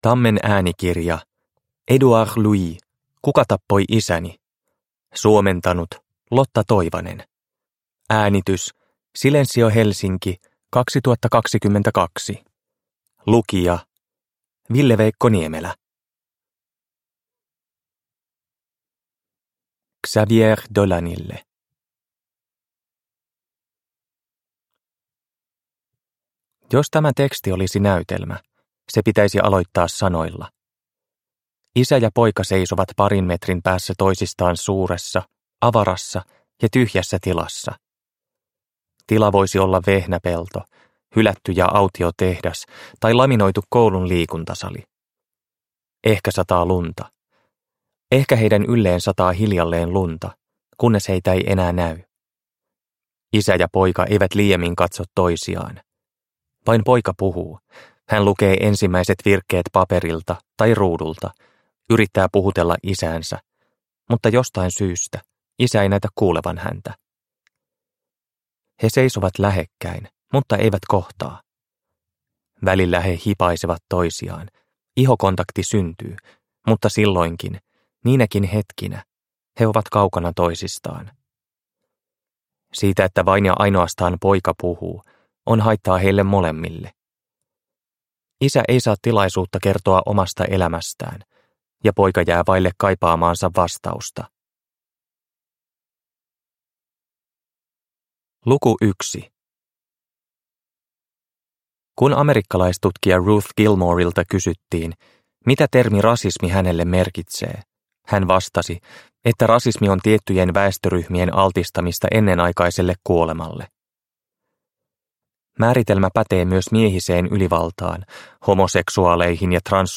Kuka tappoi isäni – Ljudbok – Laddas ner